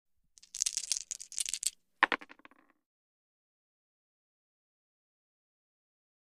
Casino Games|Dice | Sneak On The Lot
Dice Shake Roll; Dice Shake And Roll Onto Table. - Rolling Dice